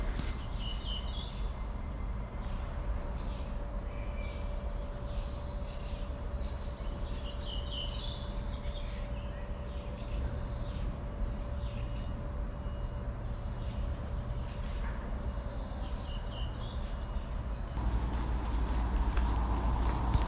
Hvilken fugl synger her
Fuglen holder til i løvskog rundt Sandvikselva i Bærum.
Fuglen høres tidlig i opptaket og litt lengre ut.
Dette er sangen fra en rødvingetrost.